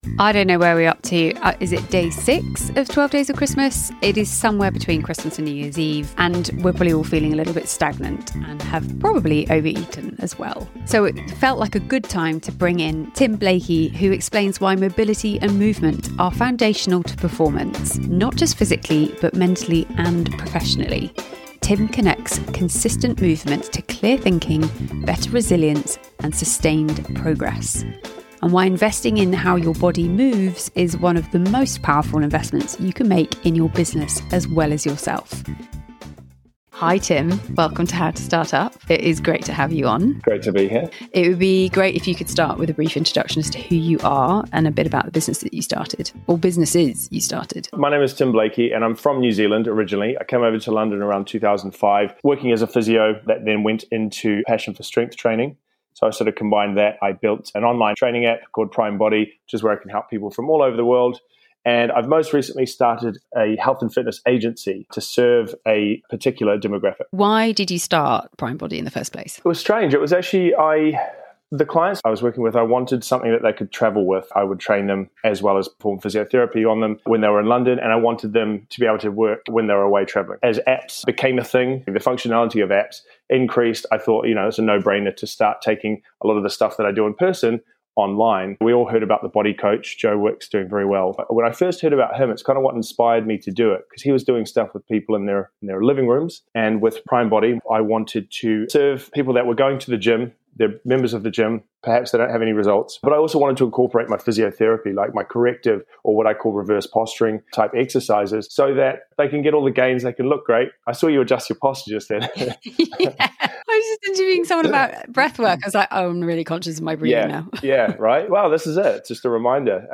In this episode, we hear from physio